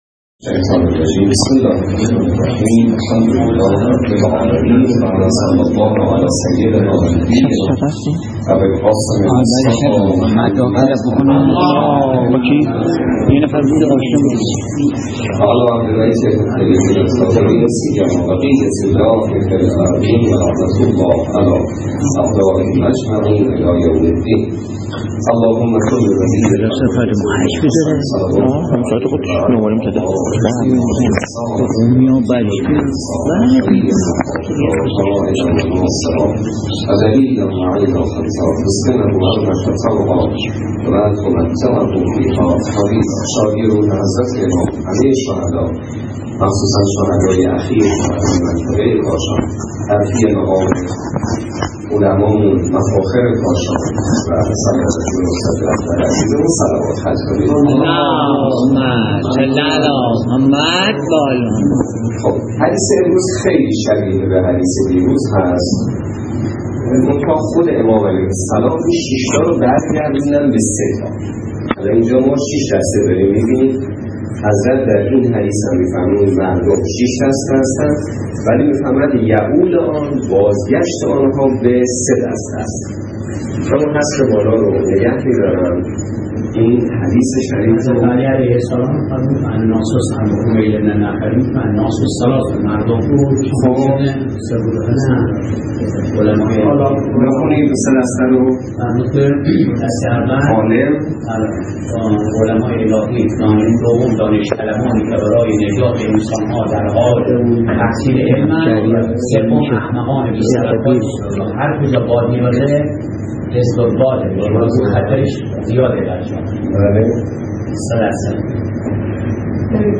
روایات ابتدای درس فقه موضوع: فقه اجاره - جلسه ۲۱